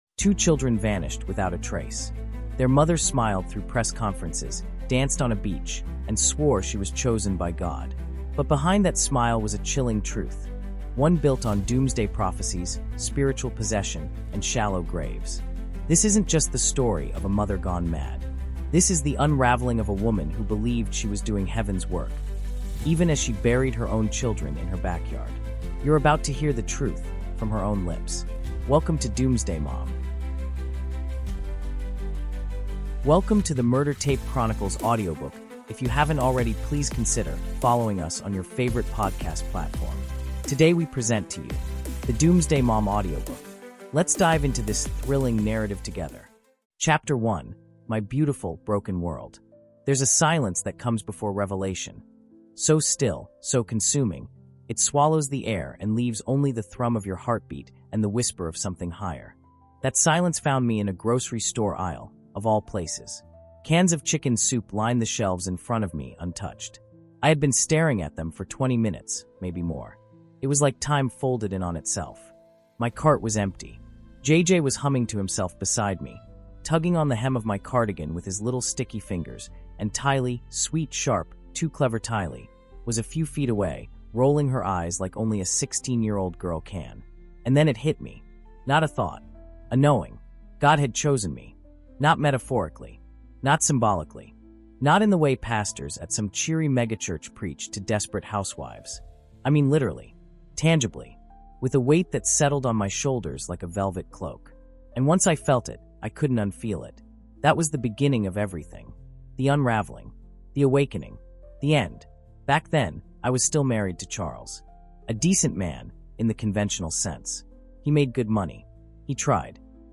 The Doomsday Mom | Audiobook
In this emotionally gripping, five-chapter true crime audiobook told from the raw first-person perspective of Lori Vallow, we dive into one of the most shocking murder cases of the decade. What began as a mother’s spiritual awakening spiraled into something far darker—culminating in the disappearance and death of her two children.